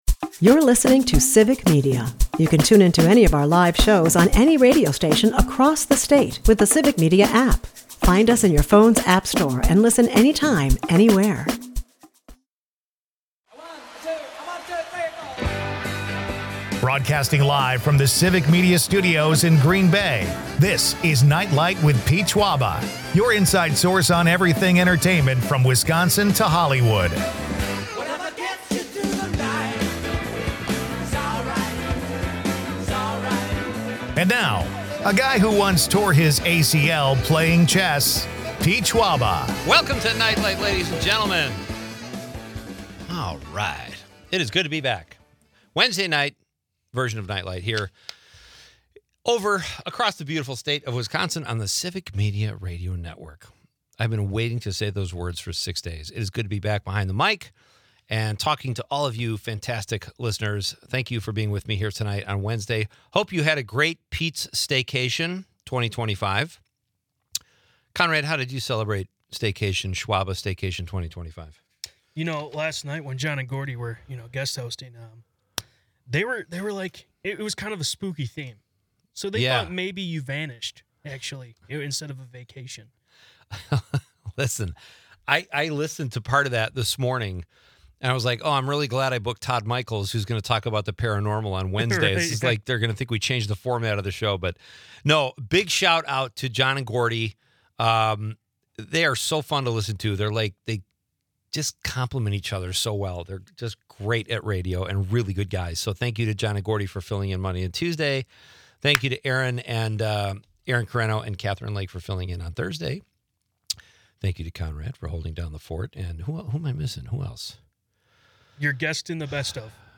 Meanwhile, Taylor Swift's past interview resurfaces humorously amidst her engagement news. The episode wraps with a contest giveaway and a nod to Wisconsin's peculiarities, blending humor, mystery, and local charm in a lively discussion.